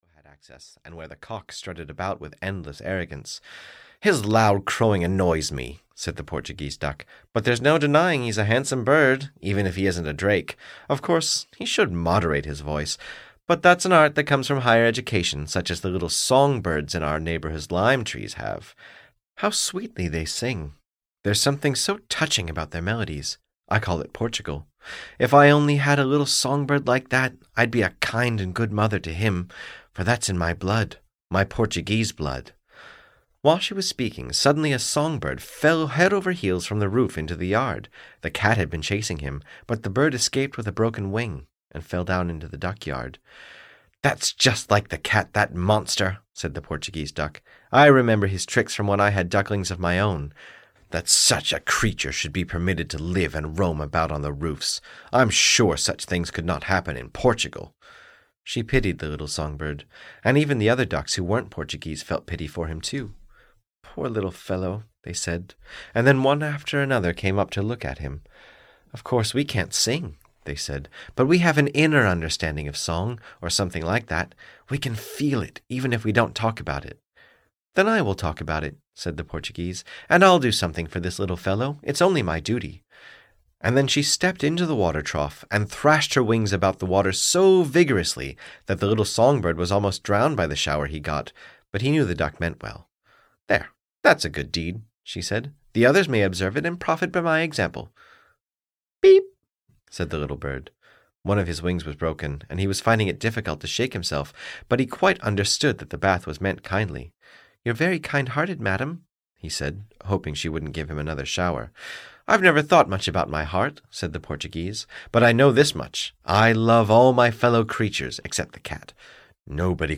In the Duck Yard (EN) audiokniha
Ukázka z knihy